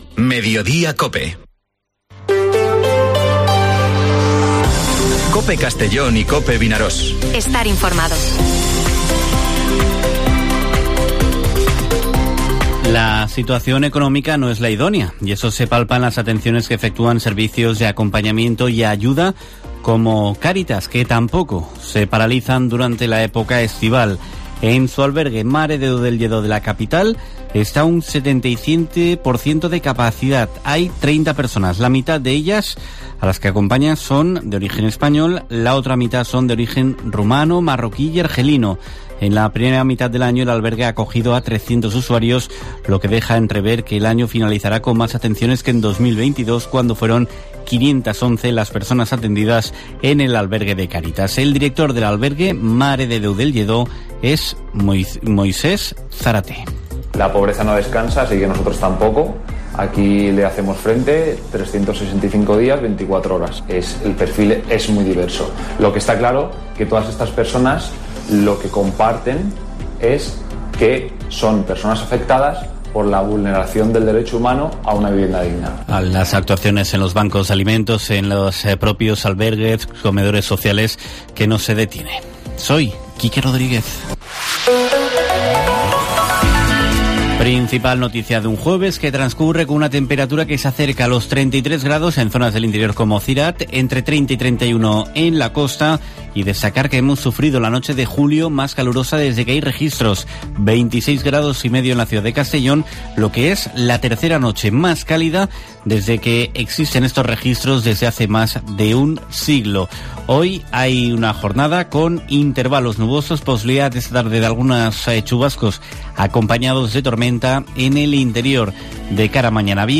Informativo Mediodía COPE en la provincia de Castellón (20/07/2023)